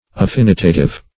Meaning of affinitative. affinitative synonyms, pronunciation, spelling and more from Free Dictionary.
Search Result for " affinitative" : The Collaborative International Dictionary of English v.0.48: Affinitative \Af*fin"i*ta*tive\, a. Of the nature of affinity.